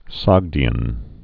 (sŏgdē-ən)